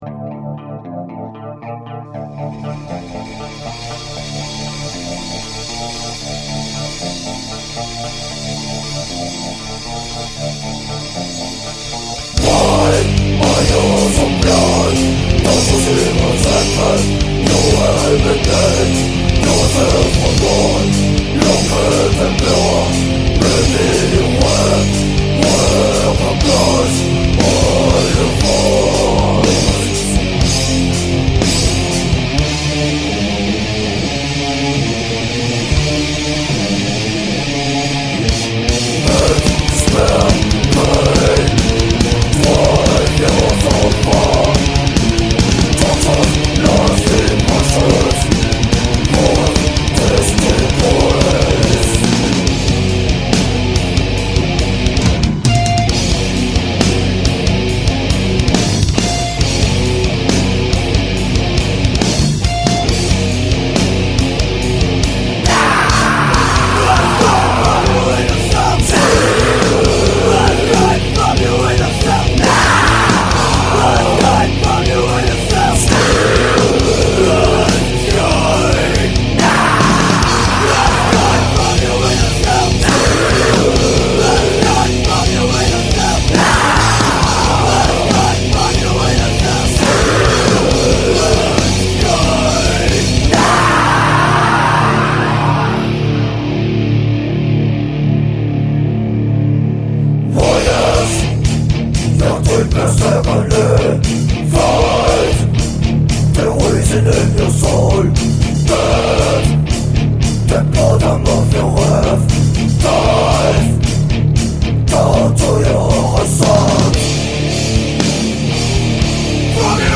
guitar, vocaks
bass
drums
Sampling and sequencing
Recorded at ATS Studios/Molln 1999